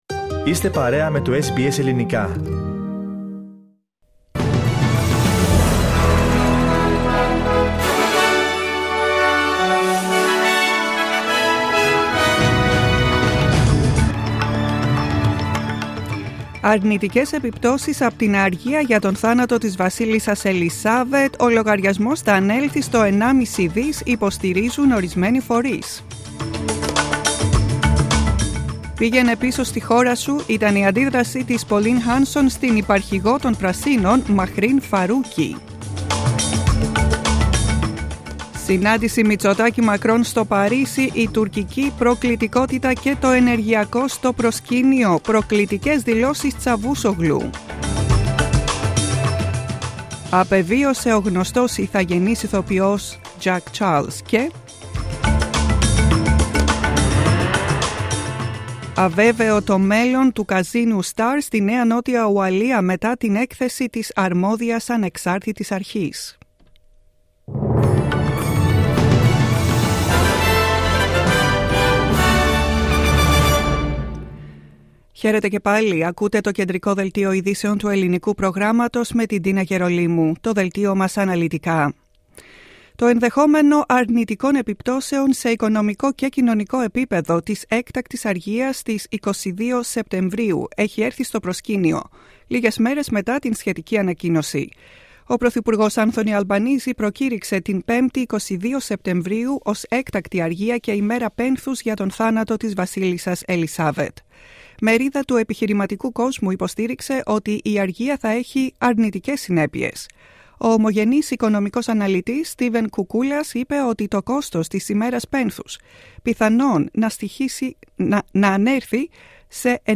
The main bulletin of the day in Greek.